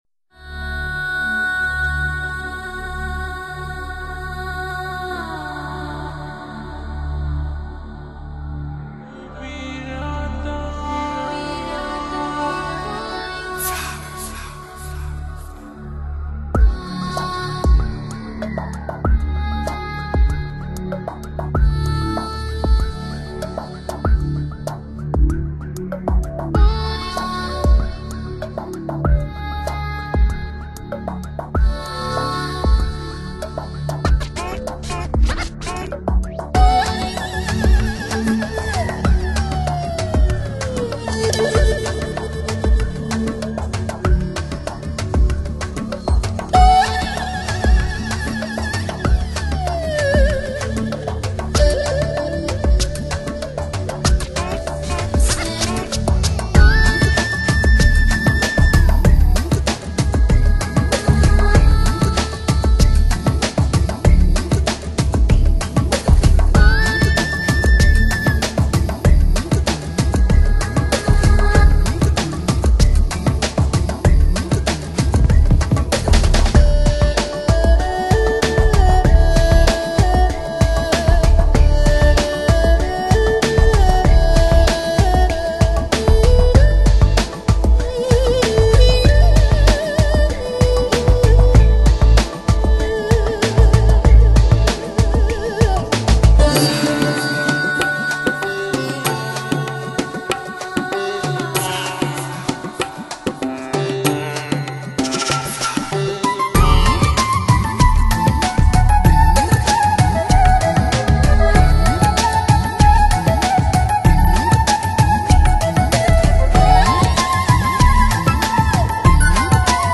感受纯美印度HI-FI音乐慢摇
总体风格流行时尚，悦耳动听，音乐幻觉，自然流露，身乐摇摆，老为新用，且形成系列化。